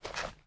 High Quality Footsteps
STEPS Dirt, Walk 08.wav